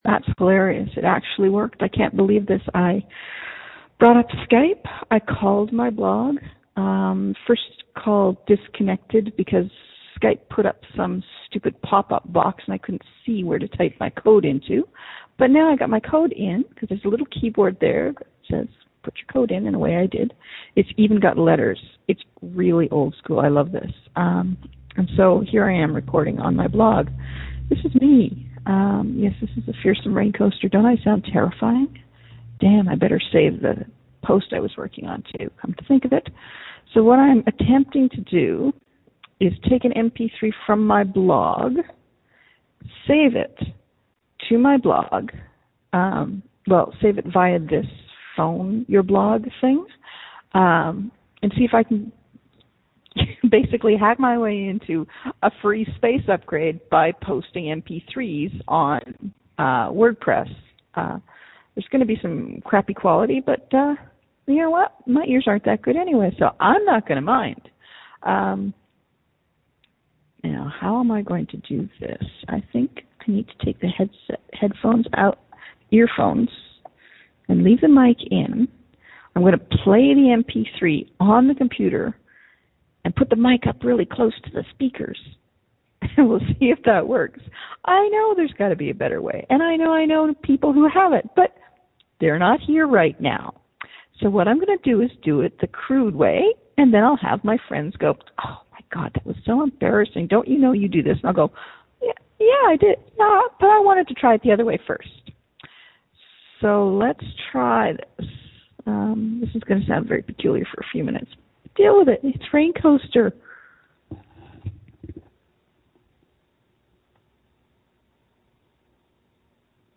I got Skype to open, and got it to call the blog. Then I input my code and started jabbering, which you can hear above.